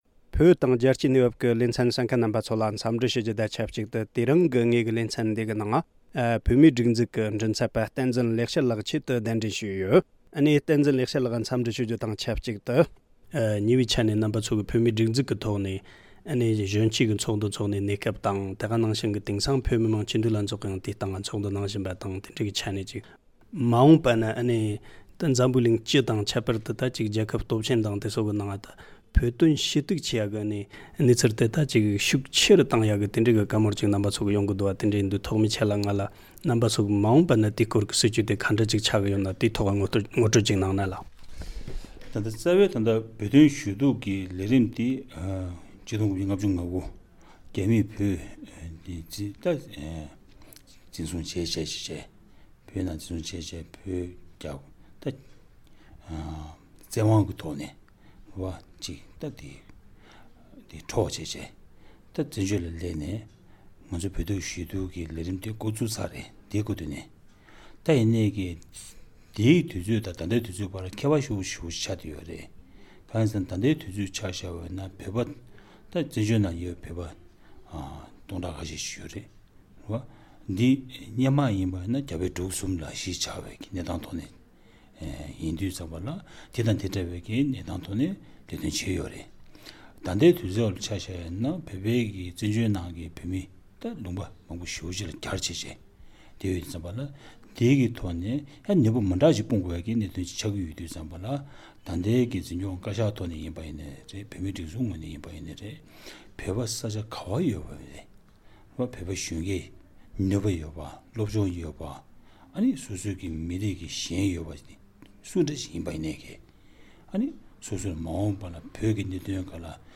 ལྷན་གླེང་མོལ་ཞུས་པར་གསན་རོགས་ཞུ།།